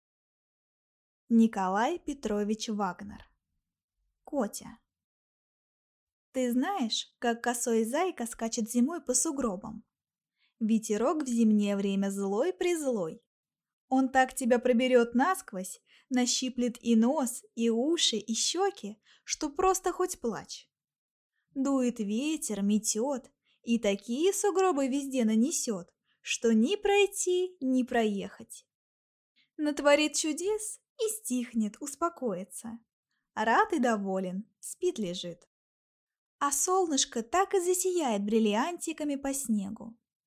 Аудиокнига Котя | Библиотека аудиокниг
Прослушать и бесплатно скачать фрагмент аудиокниги